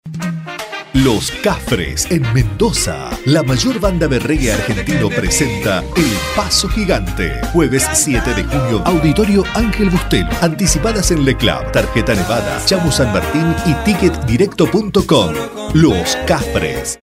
spanisch SĂŒdamerika
Sprechprobe: Sonstiges (Muttersprache):